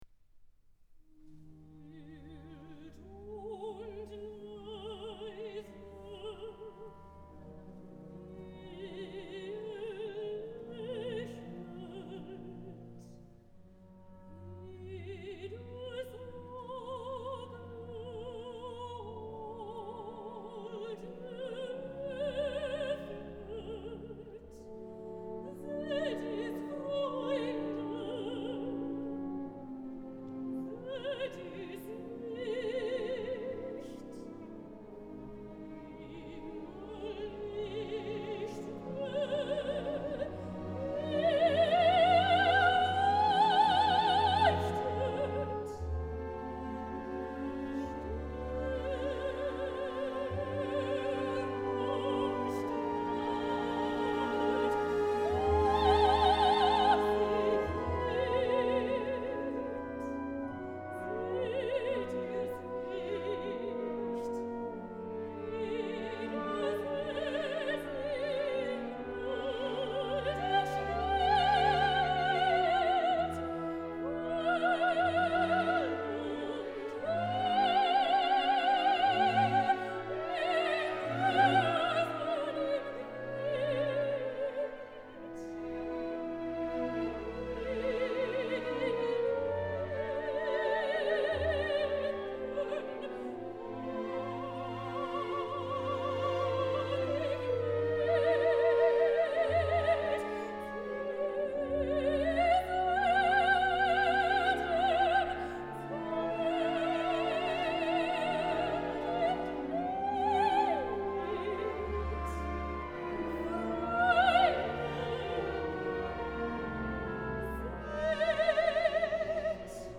Welcome — Rachel Nicholls - Soprano